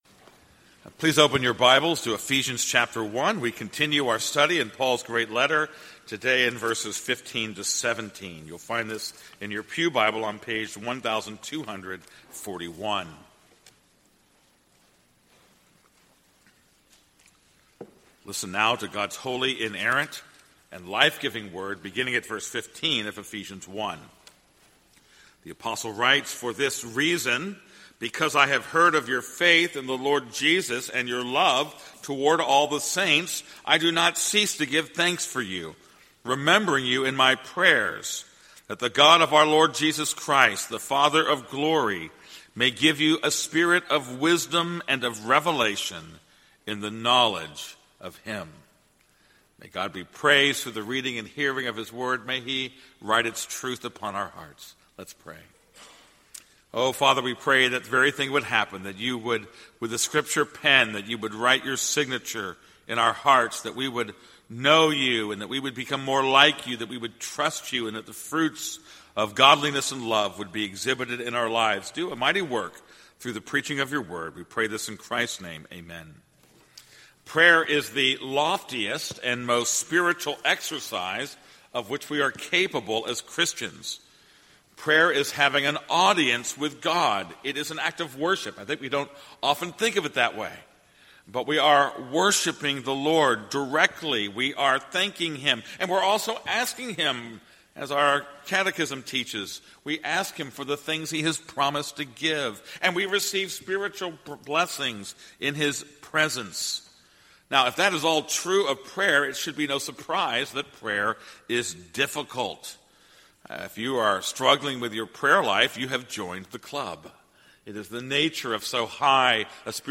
This is a sermon on Ephesians 1:15-17.